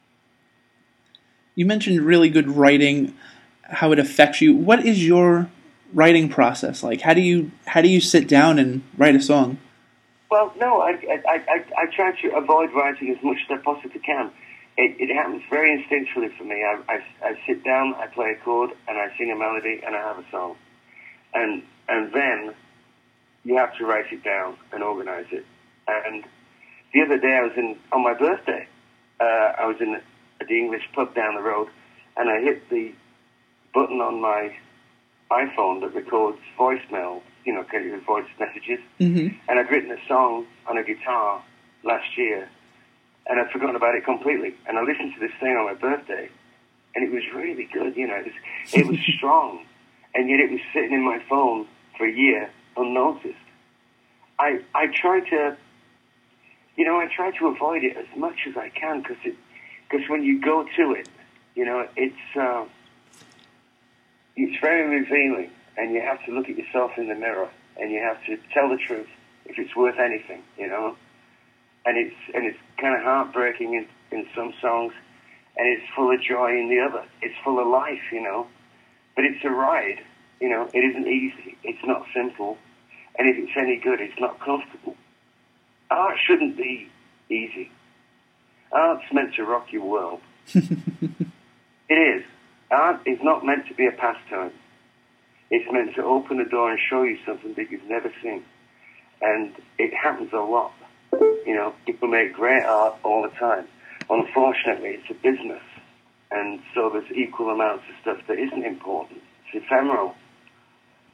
Review Fix chats with singer/songwriter John Waite, who discusses his creative process.